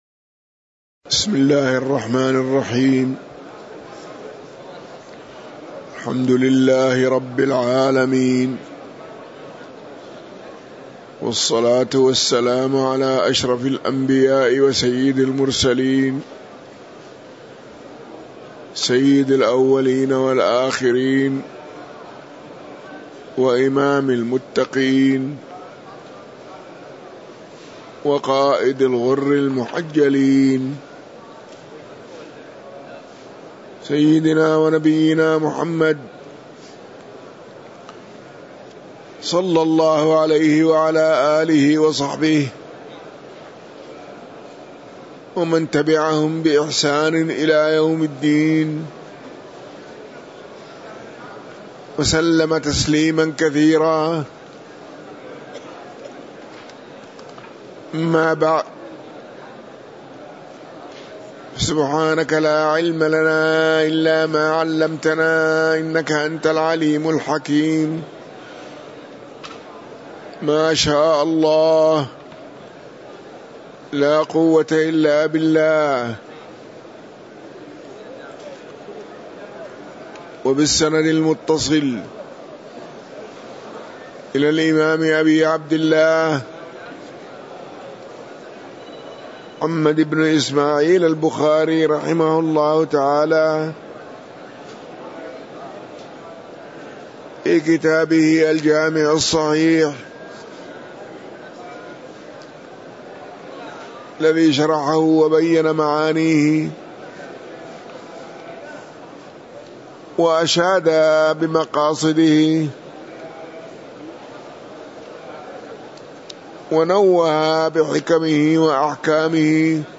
تاريخ النشر ٦ رمضان ١٤٤٤ هـ المكان: المسجد النبوي الشيخ